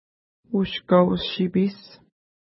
ID: 62 Longitude: -58.7457 Latitude: 52.8299 Pronunciation: u:ʃka:w-ʃi:pi:s Translation: New Antlers Lake River (small) Feature: river Explanation: Named in reference to lake Ushkau-nipi (no 60) from which it flows.